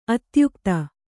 ♪ atyukta